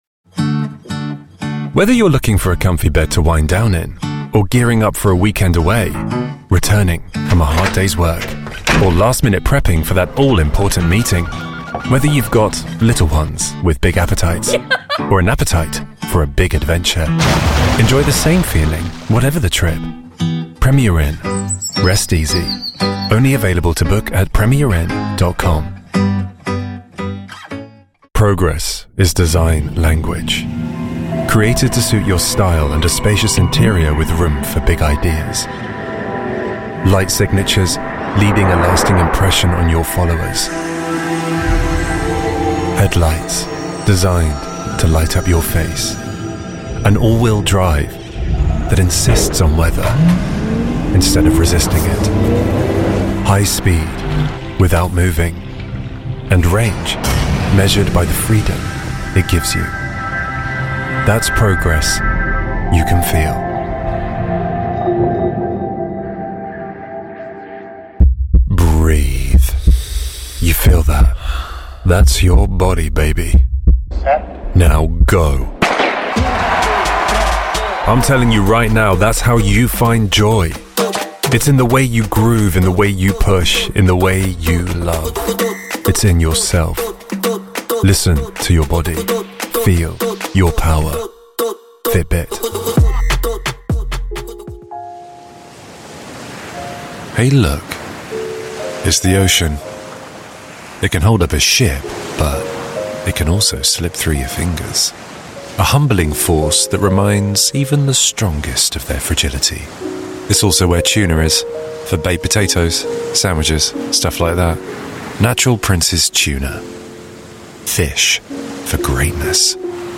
Inglés (Reino Unido)
Confiado
Atractivo
Suave